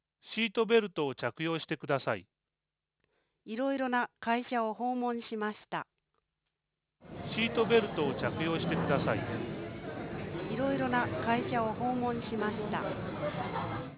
重要テク③…サンプリング周波数変換 話速変換試聴 音声フォーマット 原音 速度レート (スピーカーをクリックすると試聴できます) サンプリング周波数：8,000Hz データのビット長：16ビット チャネル数：モノラル フレーム長：128サンプル 1/3倍 1/2倍 1.5倍 2.0倍